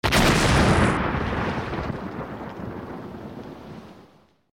OtherDestroyed3.wav